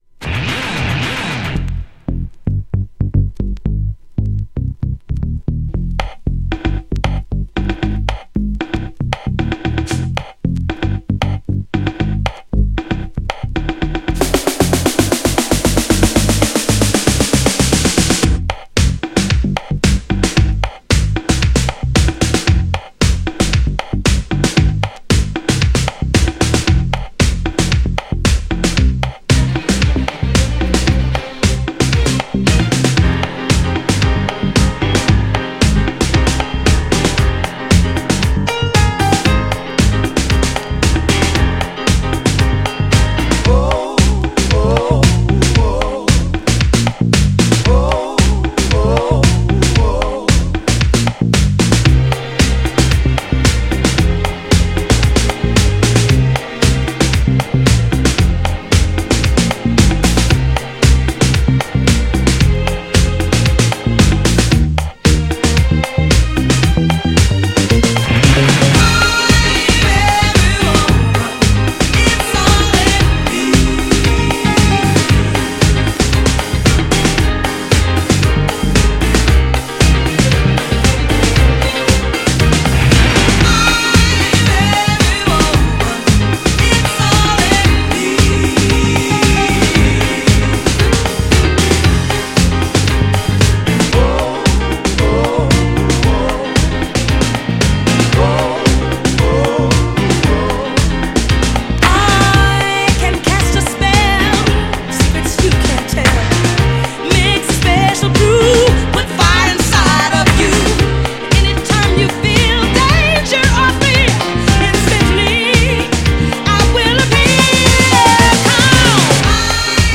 GENRE Dance Classic
BPM 81〜85BPM
# AOR
# JAZZY
# アーバン # スロー # ソウル # メロウ # 切ない感じ